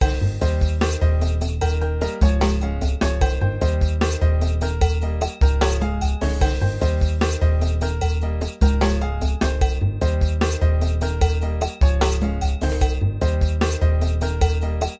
backing tracks
Phrygian Mode